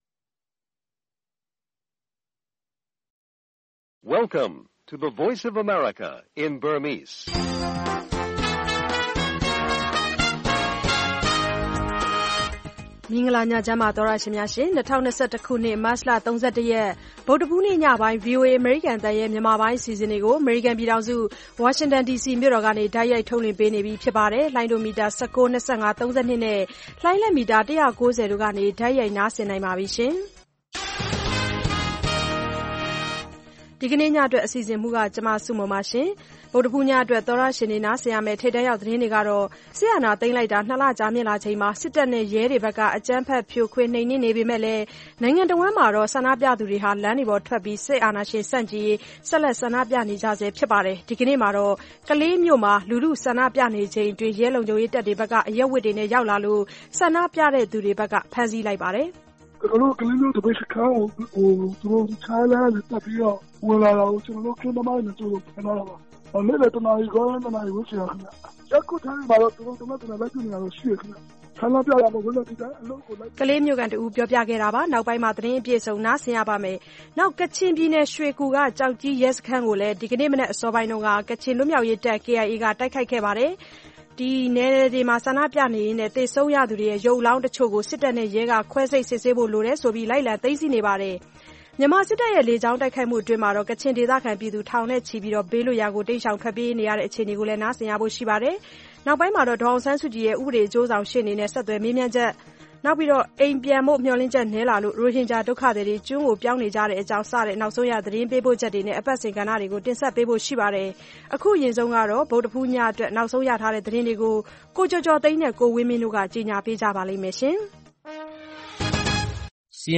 ဗုဒ္ဓဟူးည ၉း၀၀ - ၁၀း၀၀ နာရီ ရေဒီယိုအစီအစဉ်။